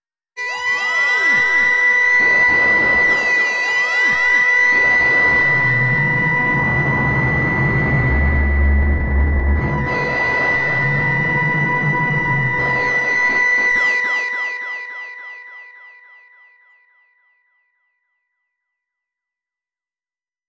SFX " 科幻咆哮式尖叫E
描述：强烈，激进的电子咆哮/尖叫。 玩E笔记。 使用Reaktor 6创建。
标签： 未来 尖叫 怪异 机械 声设计 未来 折磨 机器人 科学的 可怕的 效果 合成器 FX SFX 科幻 噪音 世界末日 怪异 数字 声音设计 奇特 机器 小说 愤怒 咆哮 电子 SoundEffect中 折磨 毛刺 抽象
声道立体声